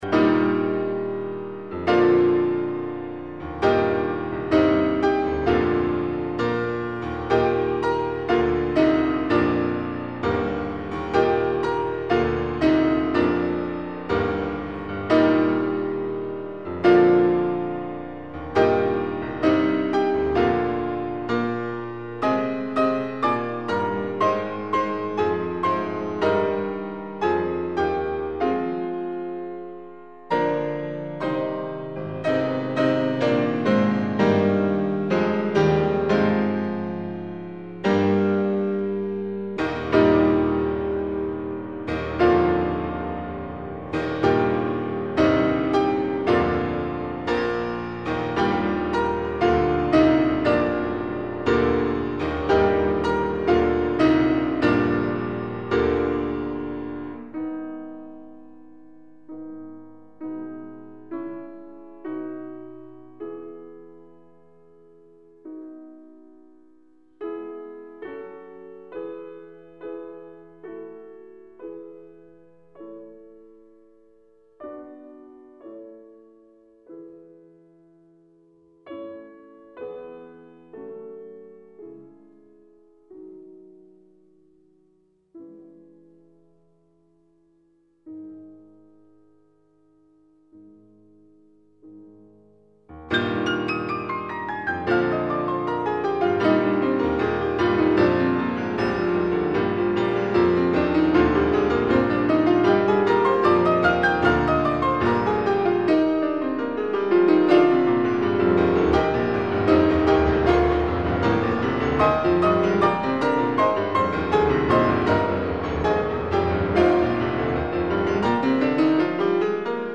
1. Tema maestoso
La grande porta di Kiev (versione pianistica)